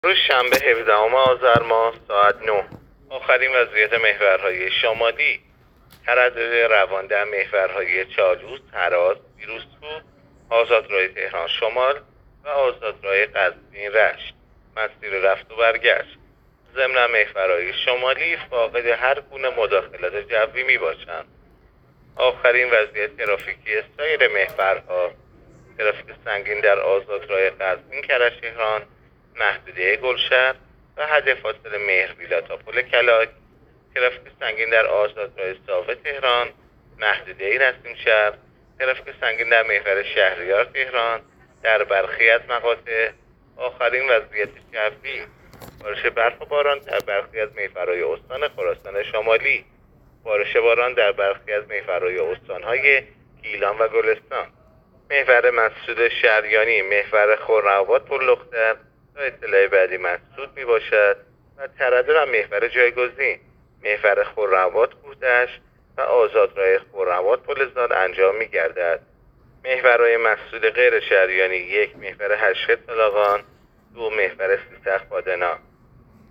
گزارش رادیو اینترنتی از آخرین وضعیت ترافیکی جاده‌ها تا ساعت ۹ هفدهم آذر؛